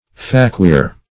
Faquir \Fa*quir"\, n.